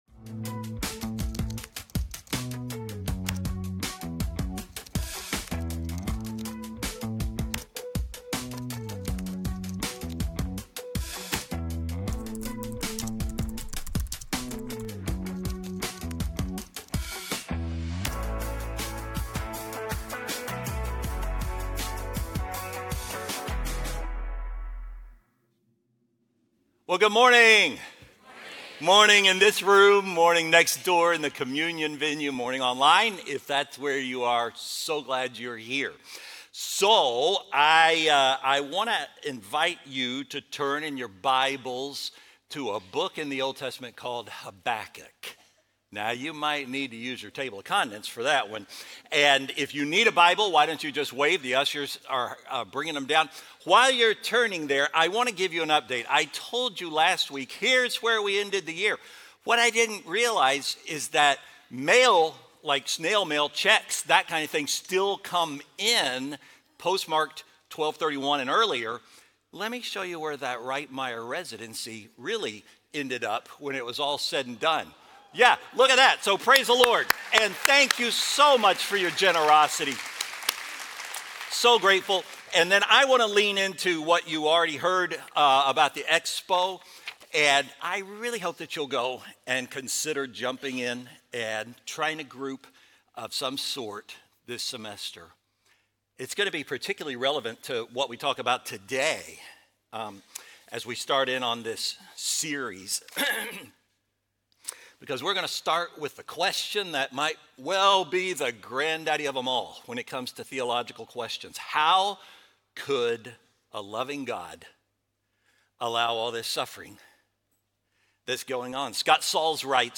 Faithbridge Sermons How Could a Loving God Allow So Much Suffering?